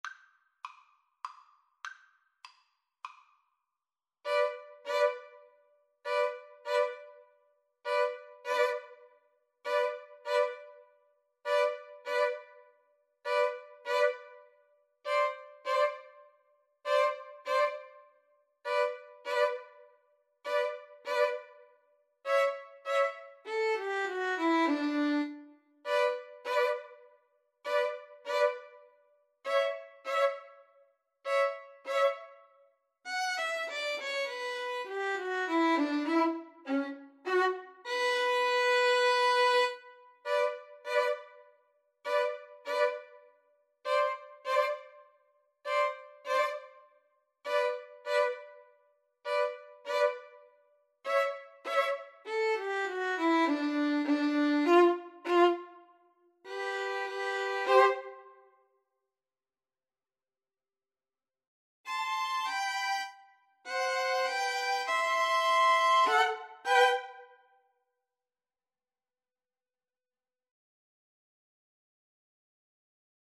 3/4 (View more 3/4 Music)
Slowly = c.100
Classical (View more Classical Violin Trio Music)